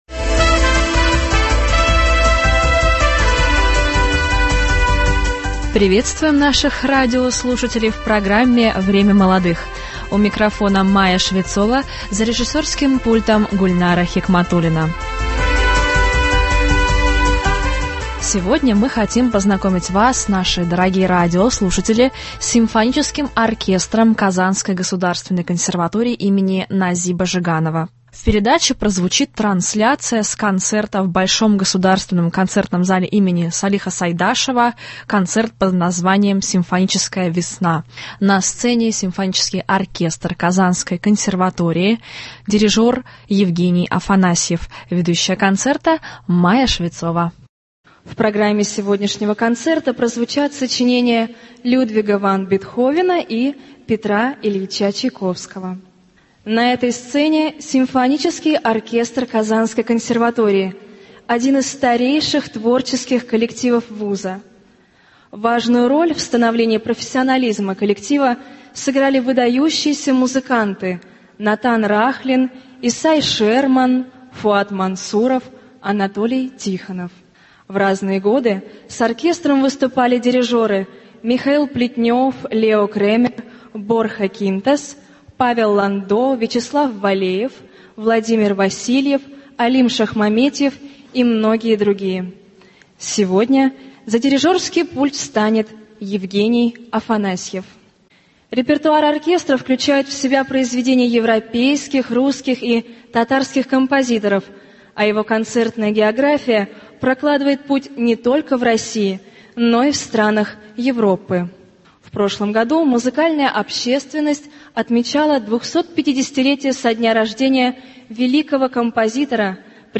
Концерт симфонического оркестра КГК имени Н. Жиганова.